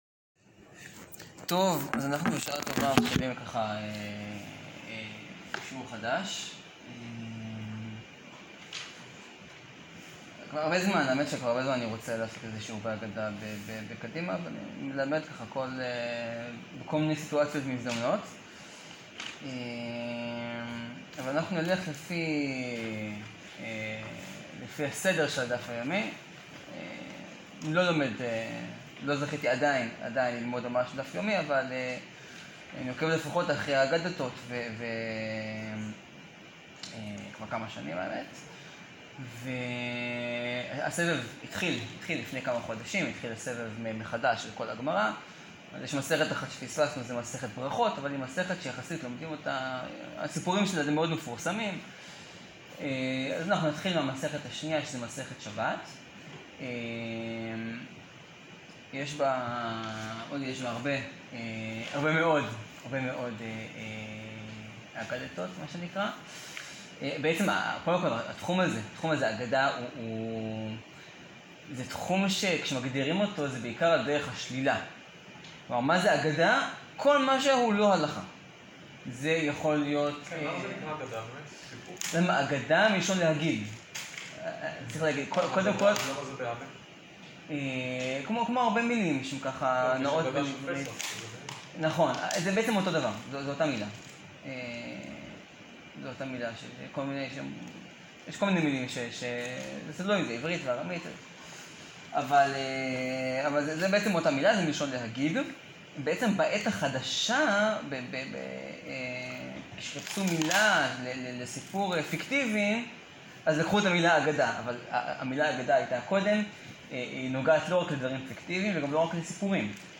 שיעור מספר 1 על אגדות מסכת שבת. האם מותר לעשות מלאכה בשביל חולה בשבת, ומה הקשר לכלבים של דוד המלך?